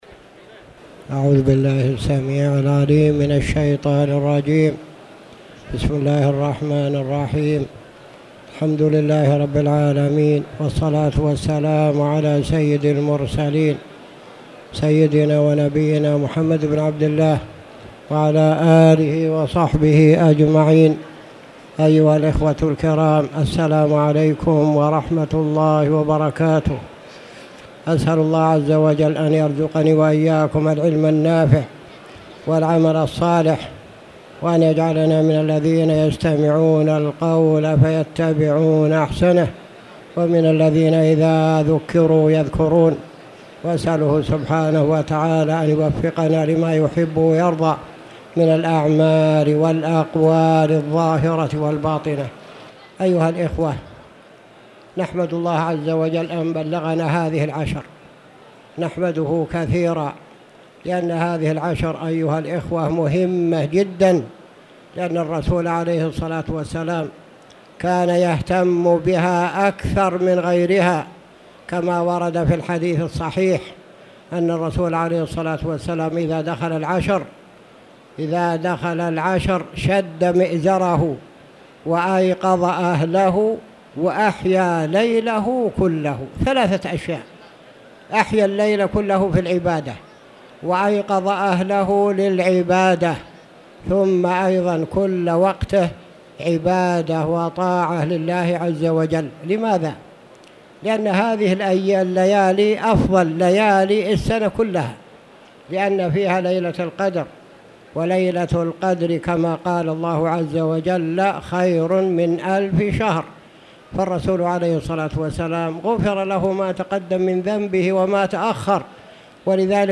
تاريخ النشر ٢٠ رمضان ١٤٣٧ هـ المكان: المسجد الحرام الشيخ